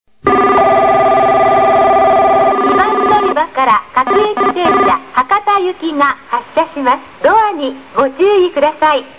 発車放送＋ベル（普通・博多）